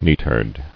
[neat·herd]